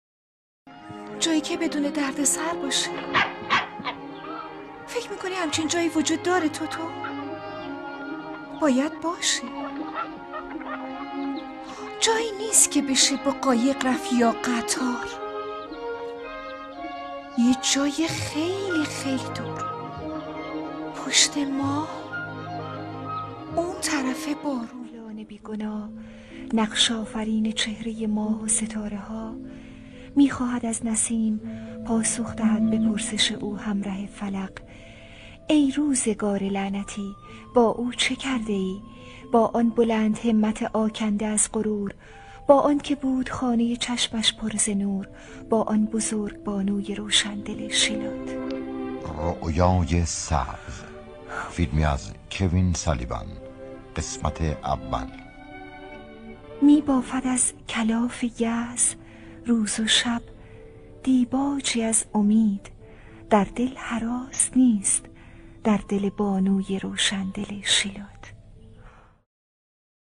نمونه کار دوبله مریم شیرزاد